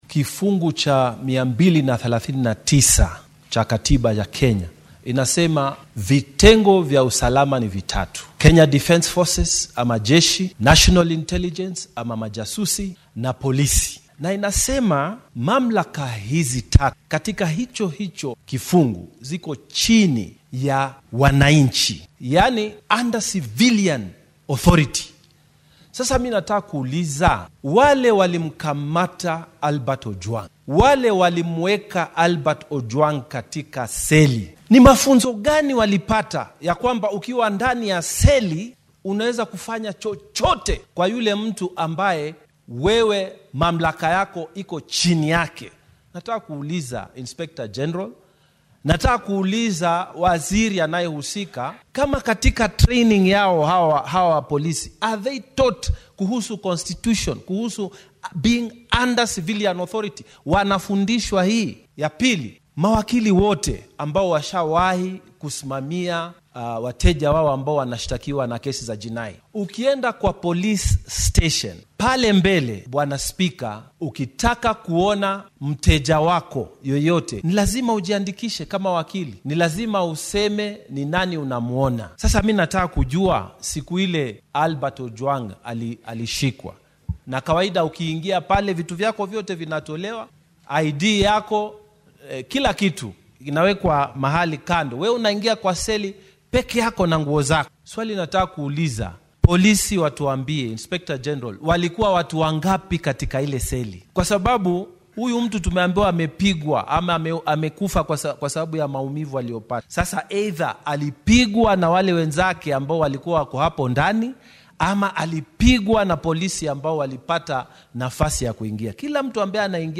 Sido kale waxaa aqalka Senetka ka soo muuqday oo su’aalo lagu weydinayay wasiirka arrimaha gudaha iyo amniga qaranka Kipchumba Murkomen iyo madaxa ciidamada dambi baarista ee dalka DCI-da Mohamed Amiin.